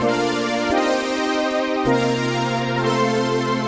AM_VictorPad_130-C.wav